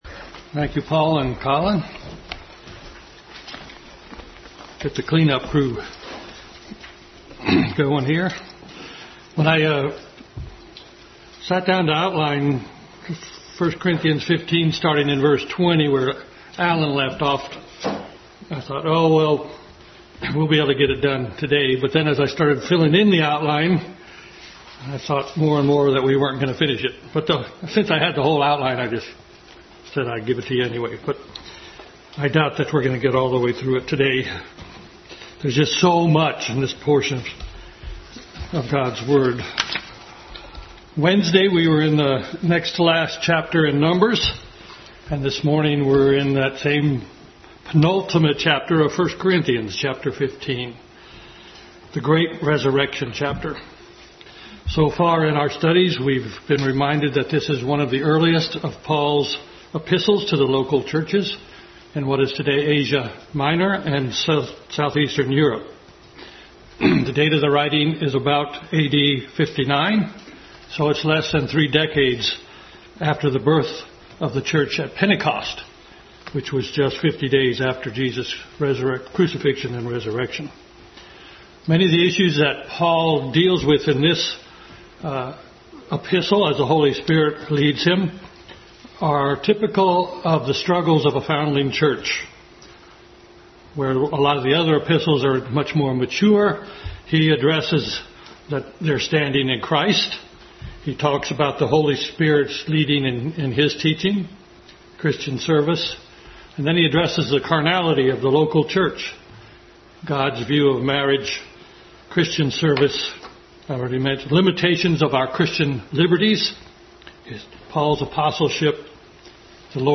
Adult Sunday School Class continued study in 1 Corinthians.